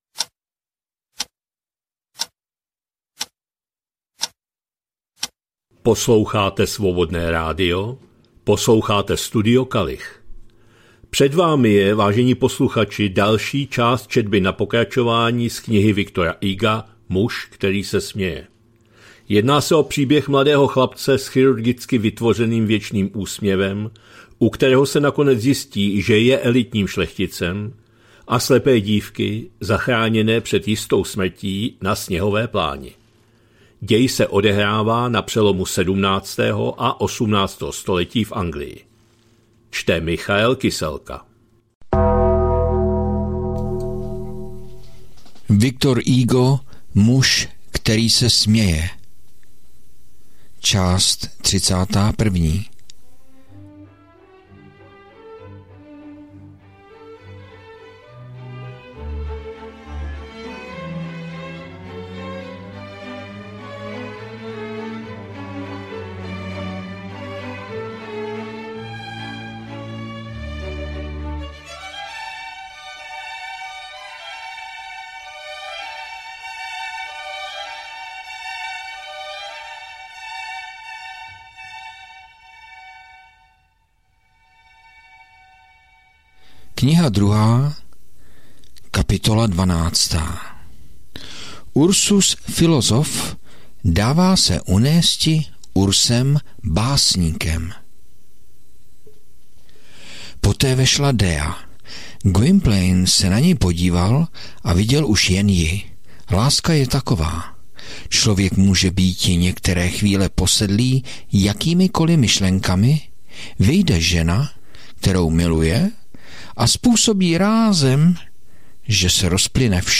2025-10-17 – Studio Kalich – Muž který se směje, V. Hugo, část 31., četba na pokračování